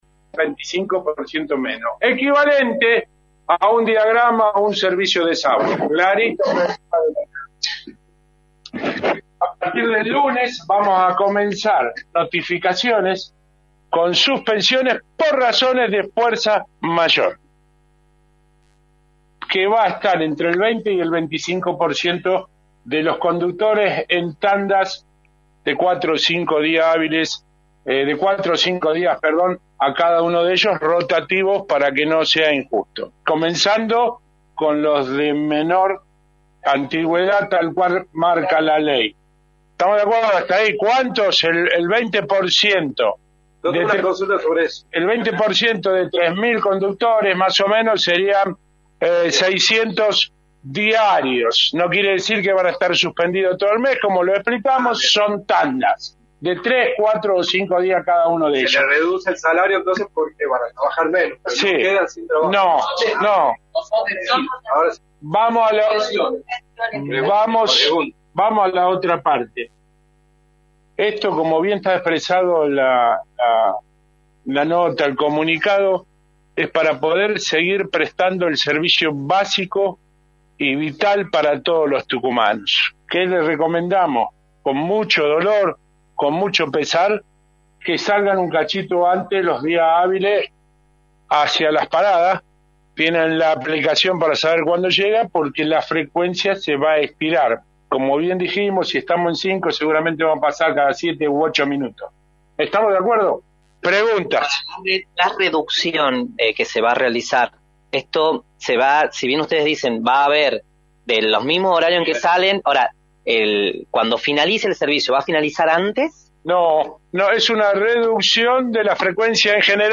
CONFERENCIA-AETAT-DEL-PLATA.mp3